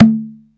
TABLA 7.WAV